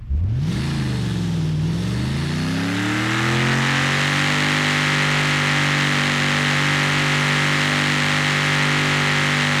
Index of /server/sound/vehicles/sgmcars/17raptor
rev.wav